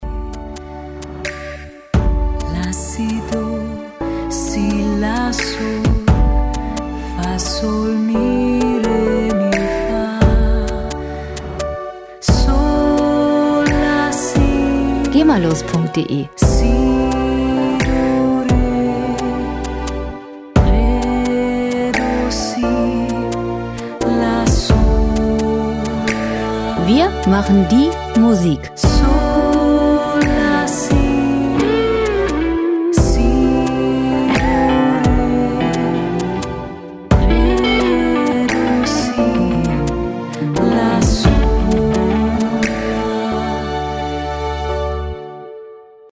• Indie Ballade
klare, gefühlvolle Stimme
harmonische E-Piano Klänge
Si“ in eine fast meditative Melodie verwandelt